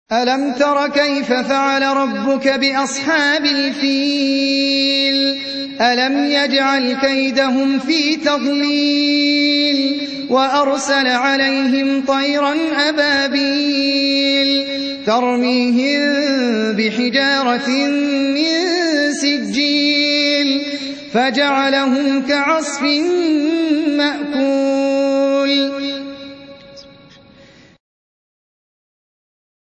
105. Surah Al-F�l سورة الفيل Audio Quran Tarteel Recitation
Surah Repeating تكرار السورة Download Surah حمّل السورة Reciting Murattalah Audio for 105. Surah Al-F�l سورة الفيل N.B *Surah Includes Al-Basmalah Reciters Sequents تتابع التلاوات Reciters Repeats تكرار التلاوات